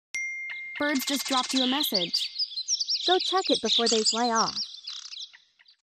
SMS ringtone